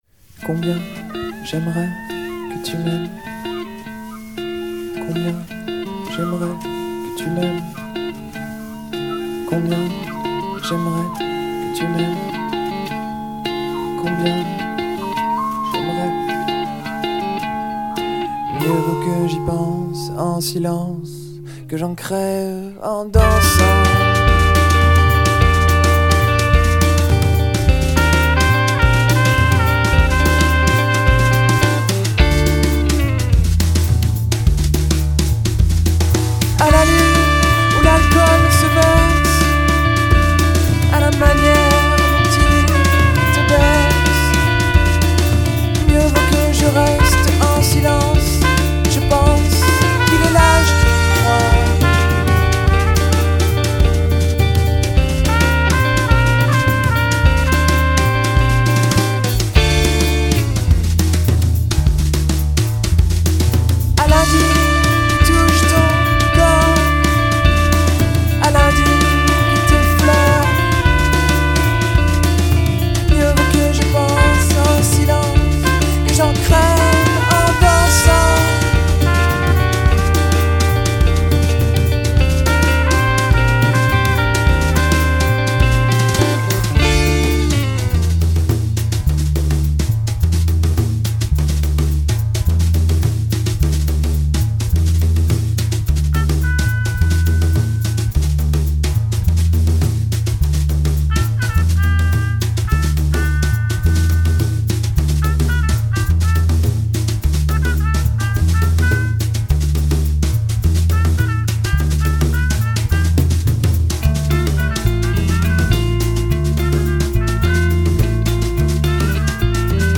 Rock, Pop
Pièce musicale inédite